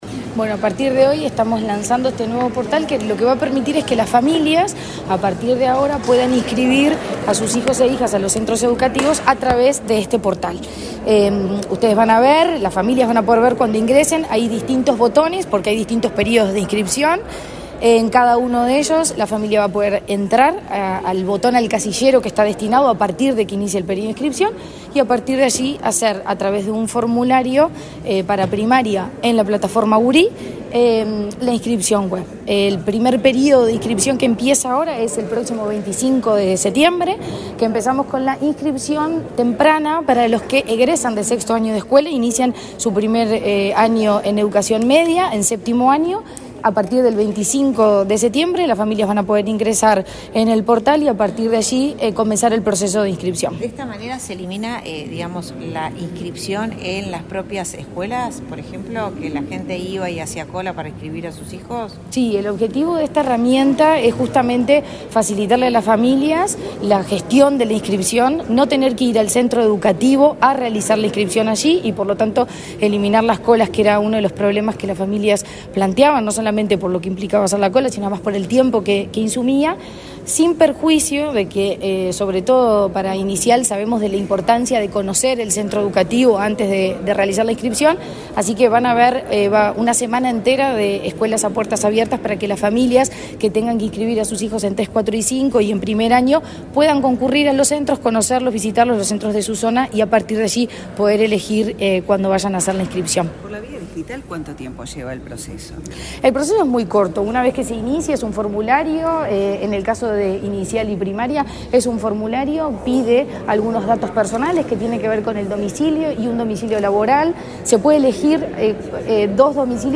Declaraciones de la presidenta de la ANEP, Virginia Cáceres
Declaraciones de la presidenta de la ANEP, Virginia Cáceres 12/09/2024 Compartir Facebook X Copiar enlace WhatsApp LinkedIn Este jueves 12, la presidenta de la Administración Nacional de Educación Pública (ANEP), Virginia Cáceres, dialogó con la prensa, antes de nuevo presentar el nuevo portal de inscripción web para estudiantes de educación pública.